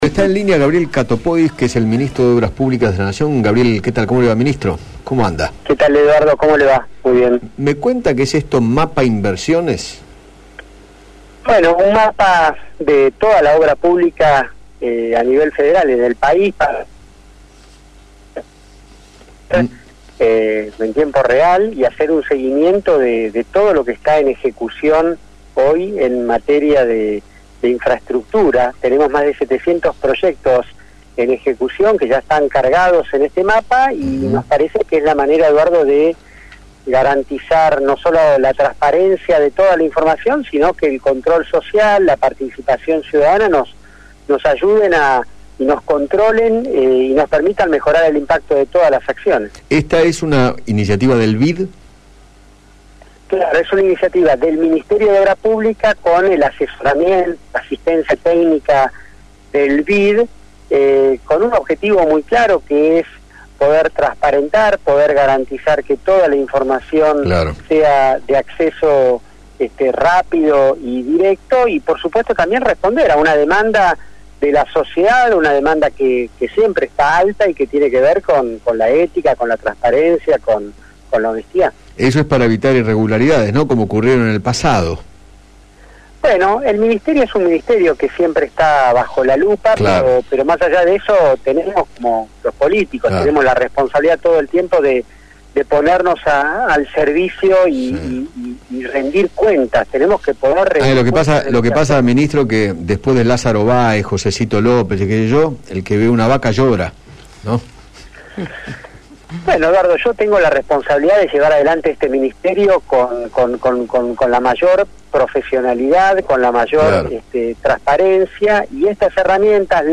Gabriel Katopodis, ministro de Obras Públicas, dialogó con Eduardo Feinmann sobre la nueva plataforma online que desarrolló el Ministerio junto al Banco Interamericano de Desarrollo para el control de la ejecución de la obra pública.